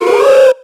Cri de Hoothoot dans Pokémon X et Y.